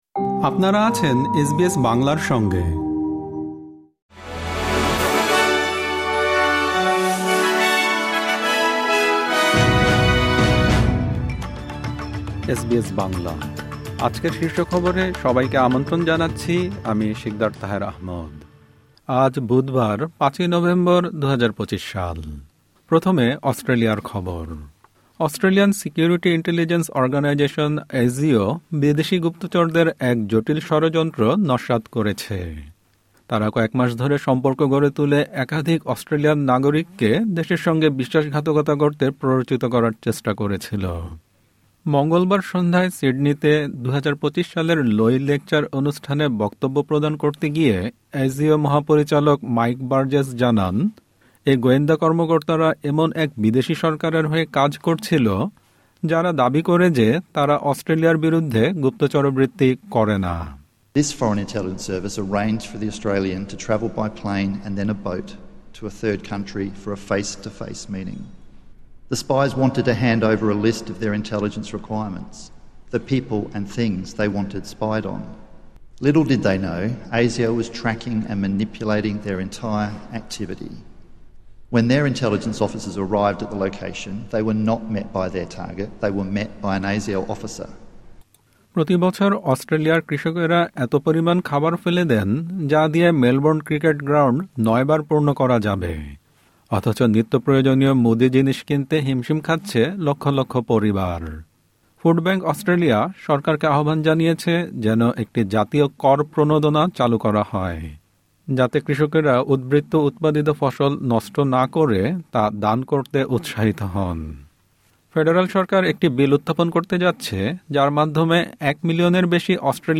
এসবিএস বাংলা শীর্ষ খবর: ৫ নভেম্বর, ২০২৫